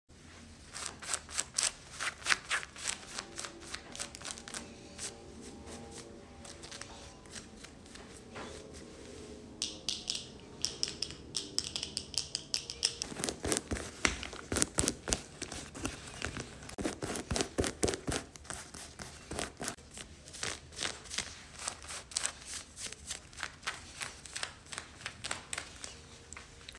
Another OOTD asmr 💜 Love the sounds of fabric scratching 🙌